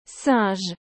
O jeito certo é algo como “sãj”, com um som nasal.
O som é nasal: “sãj”.
Singe.mp3